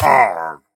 Minecraft Version Minecraft Version latest Latest Release | Latest Snapshot latest / assets / minecraft / sounds / mob / pillager / hurt2.ogg Compare With Compare With Latest Release | Latest Snapshot
hurt2.ogg